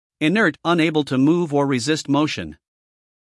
英音/ ɪˈnɜːt / 美音/ ɪˈnɜːrt /